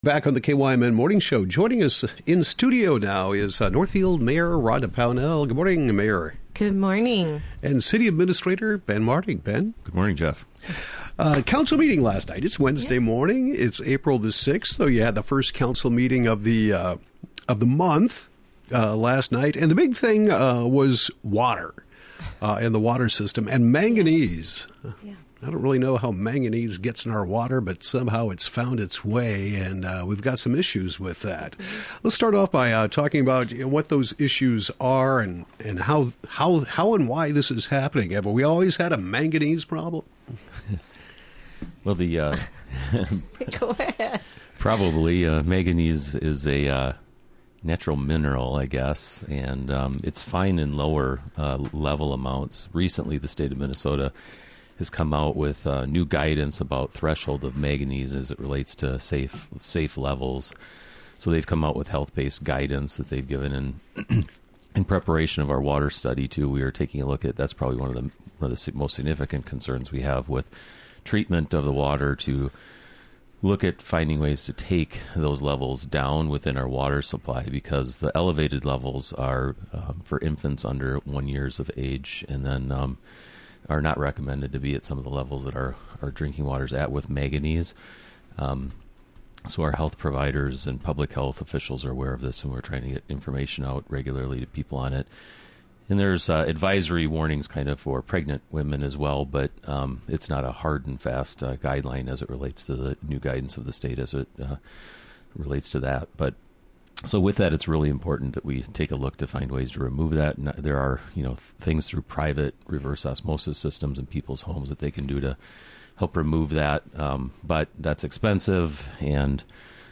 Northfield Mayor Rhonda Pownell and City Administrator Ben Martig discuss the April 4 City Council meeting. Topics include updates to the wastewater treatment plant, mill and overlay and bike lanes projects.